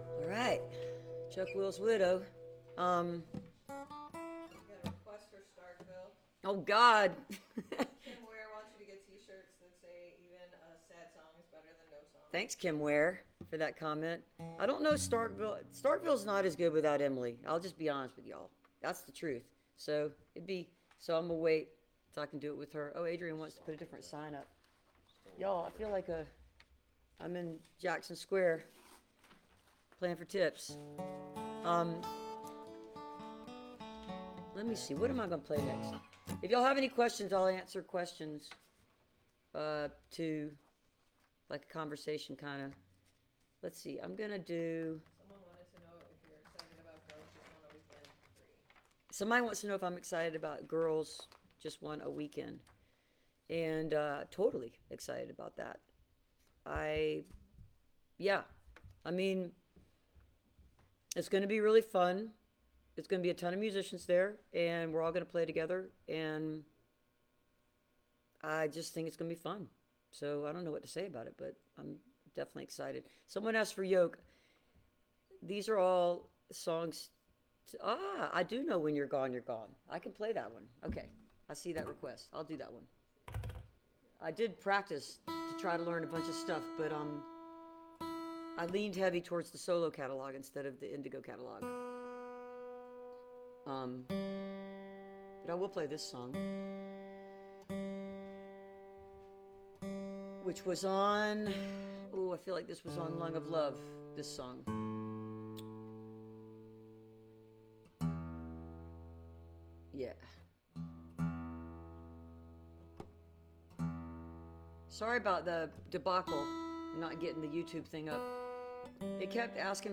(captured from the facebook live stream)
05. talking with the crowd (2:07)